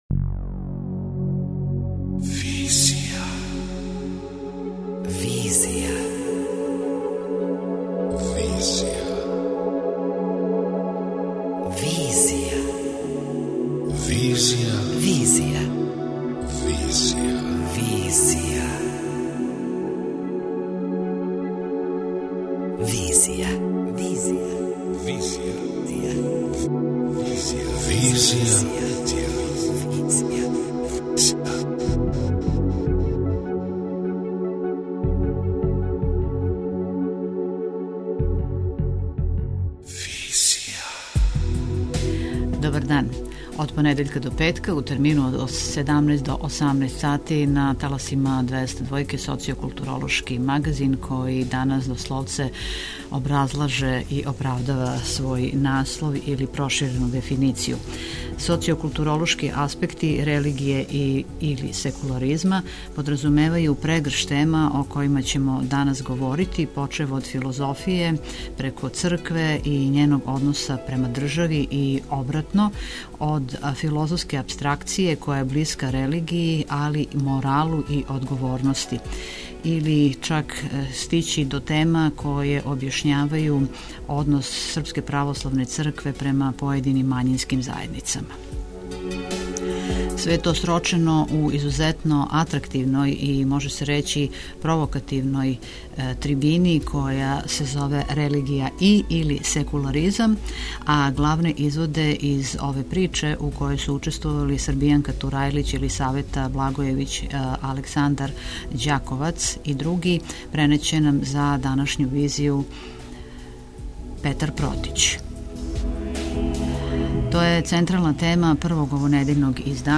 Преносимо узбудљиву полемику са трибине ’’Религија и/или секуларизам’’ одржане у Малој сали Коларчеве задужбине, 10. новембра.